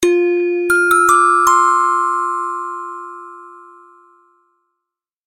Электронный звук: время истекло